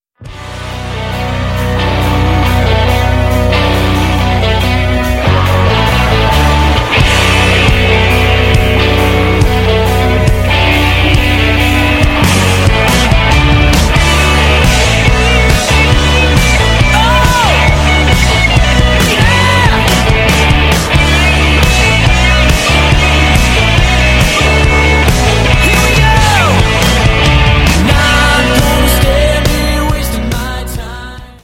energiegeladenen Lobpreis
• Sachgebiet: Praise & Worship